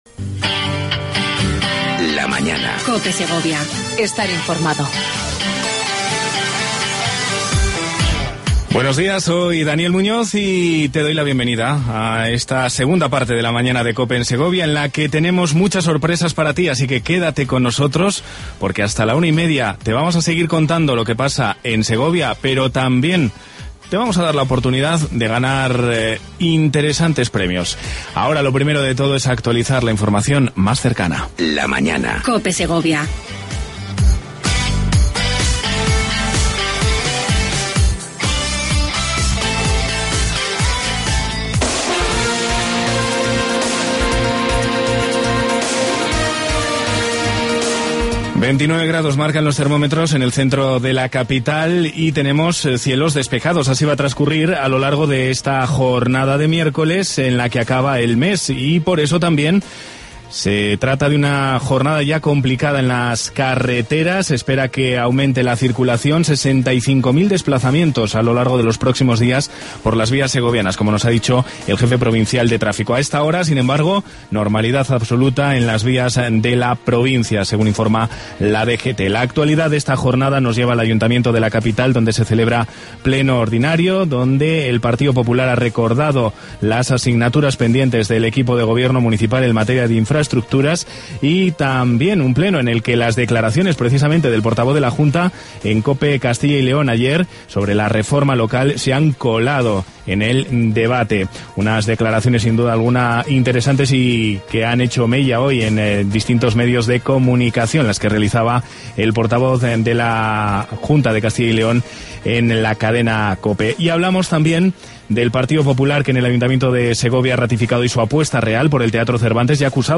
AUDIO: Abrimos la Playa en el Acueducto para jugar con los oyentes de La Mañana de COPE en Segovia.